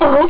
00070_Sound_sealion.mp3